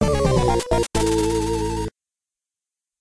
This file is an audio rip from a(n) TurboGrafx-16 game.